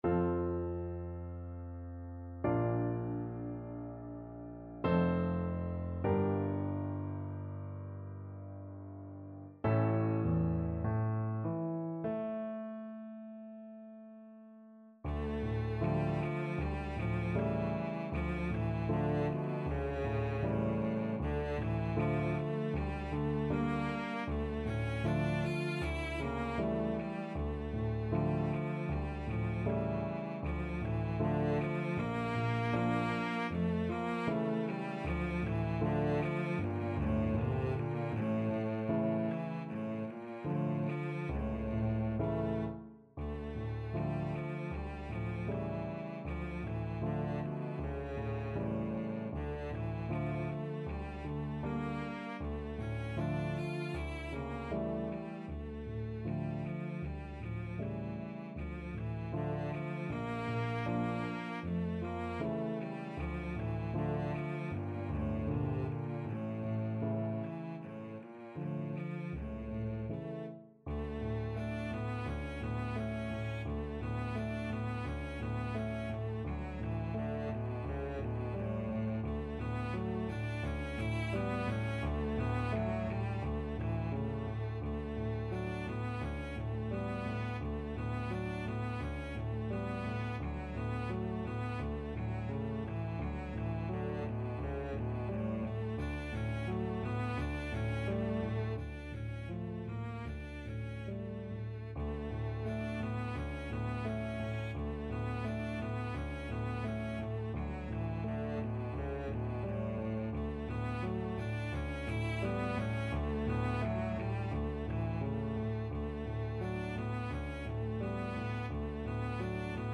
Classical Mussorgsky, Modest Une Larme (A Tear) Cello version
Cello
4/4 (View more 4/4 Music)
F major (Sounding Pitch) (View more F major Music for Cello )
Largo
Classical (View more Classical Cello Music)